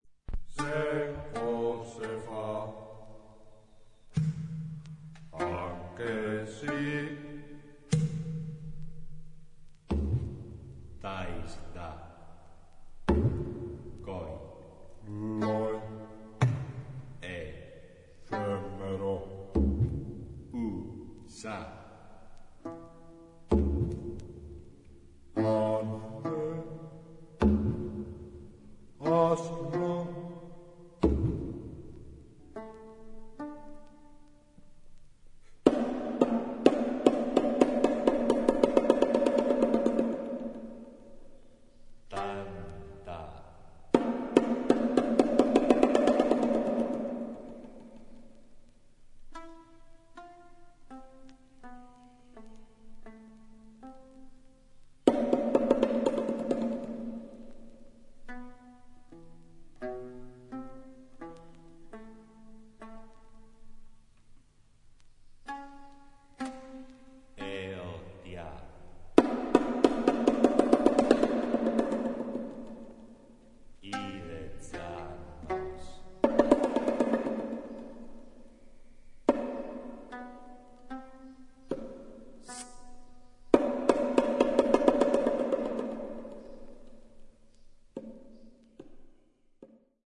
細部に至るまでをも表現するべく、当時の楽器36種類を復元・制作。